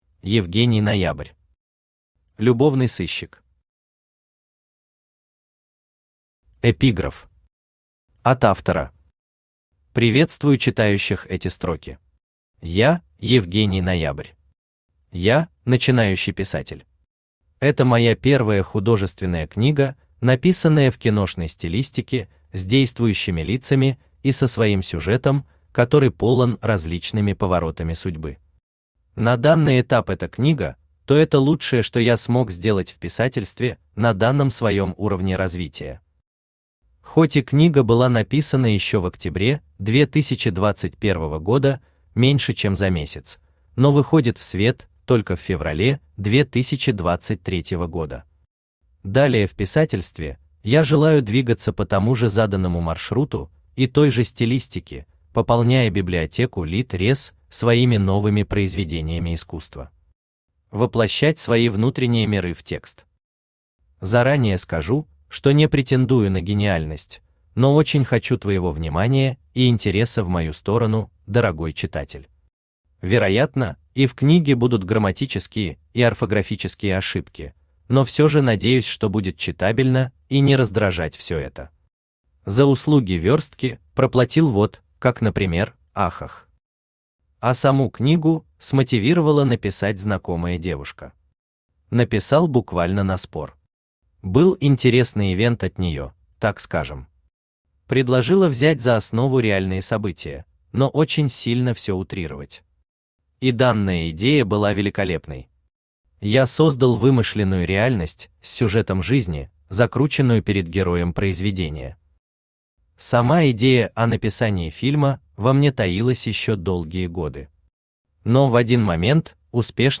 Аудиокнига Любовный сыщик | Библиотека аудиокниг
Aудиокнига Любовный сыщик Автор Евгений Ноябрь Читает аудиокнигу Авточтец ЛитРес.